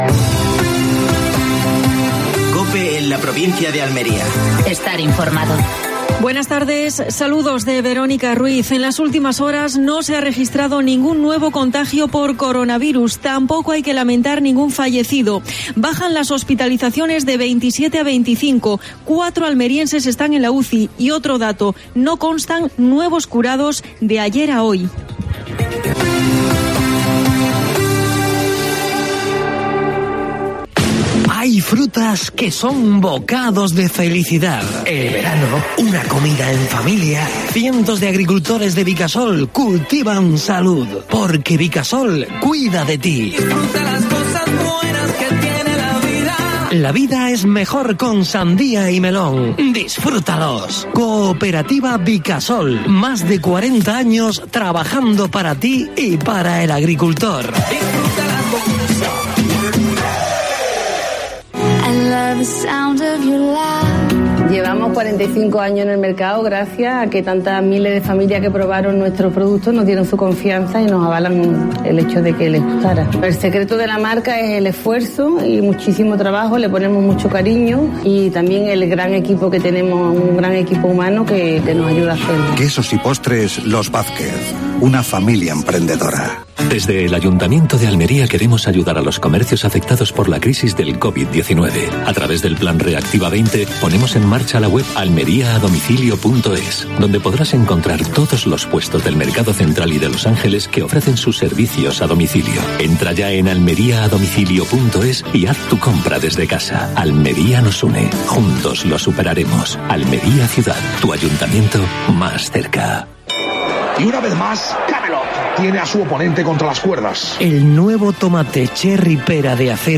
Última hora en Almería. Sin nuevos casos de coronavirus en la provincia. Entrevista